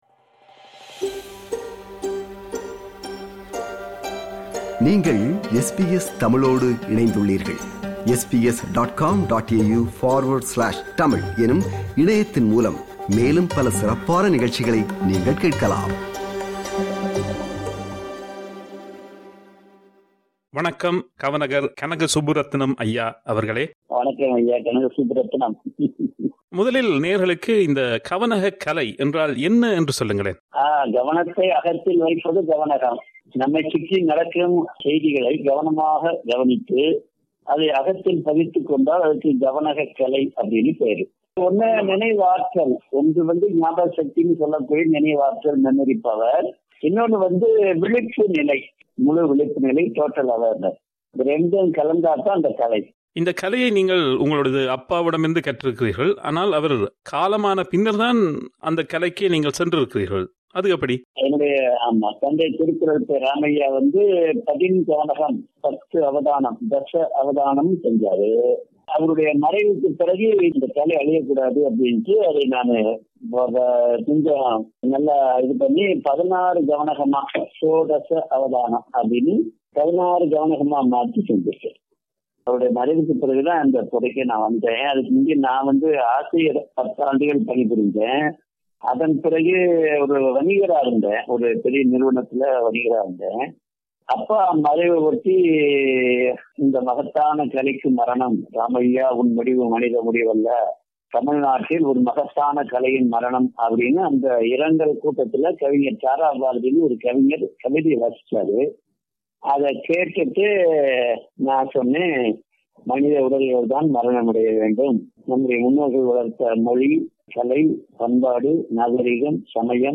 2019ஆம் ஆண்டு ஒலிபரப்பான நேர்காணலின் மறு ஒலிபரப்பு இது.